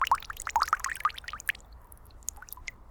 water-dribbling-01
Category 🌿 Nature
bath bubble burp click drain dribble dribbling drip sound effect free sound royalty free Nature